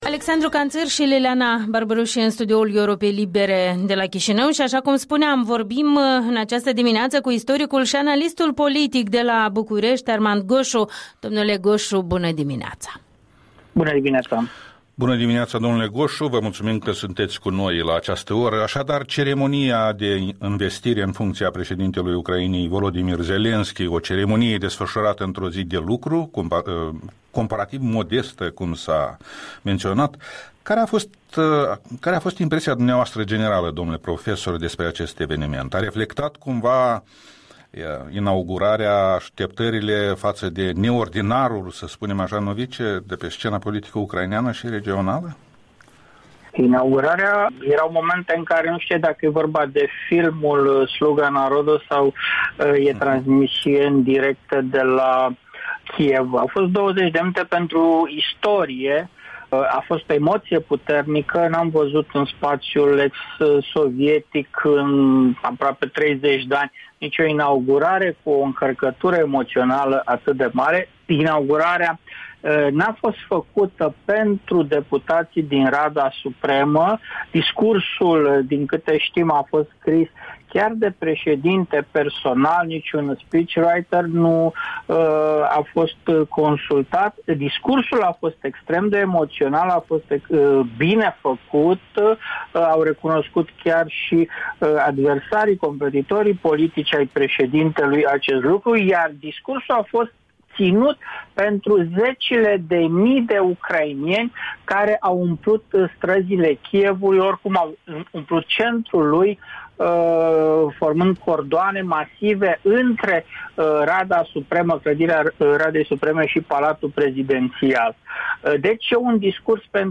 Interviul dimineții cu un analist bucureștean despre schimbările în curs în Ucraina, odată cu venirea la putere a lui Volodimir Zelenski.